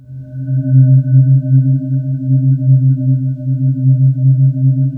Index of /90_sSampleCDs/USB Soundscan vol.28 - Choir Acoustic & Synth [AKAI] 1CD/Partition D/05-SPECTRE